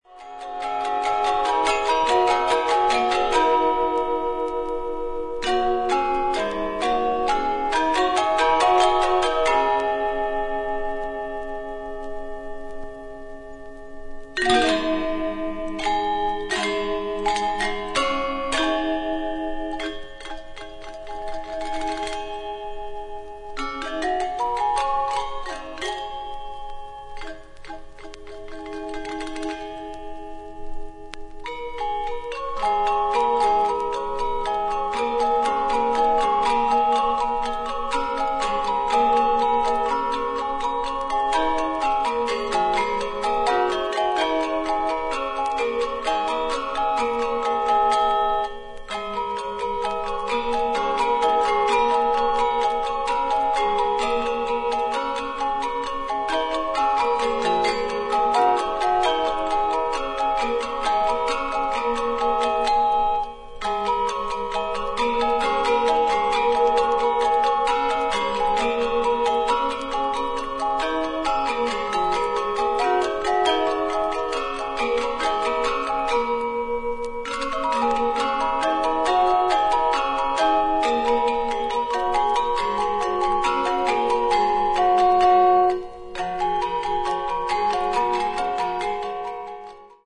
ARTIST : GAMELAN GONG SEKAR ANJAR, GENDER WAJANG QUARTET, DR. MANTLE HOOD